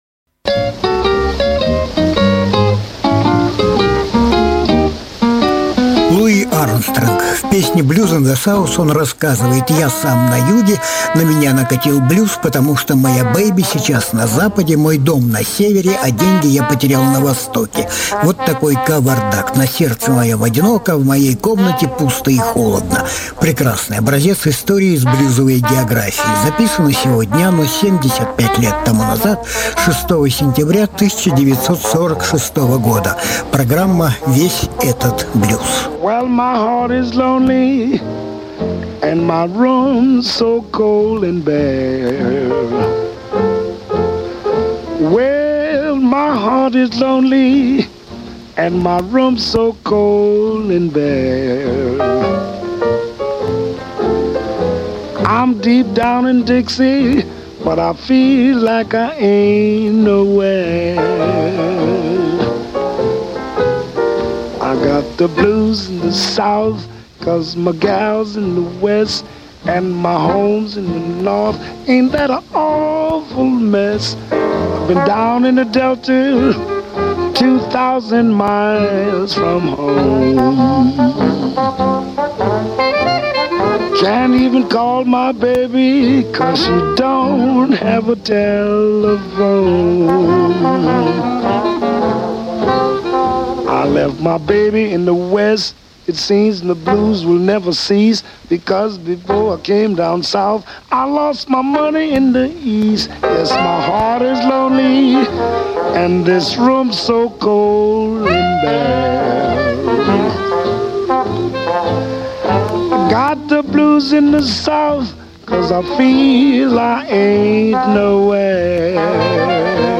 Альбом: Разные альбомы Жанр: Блюзы и блюзики СОДЕРЖАНИЕ 06.09.2021 1.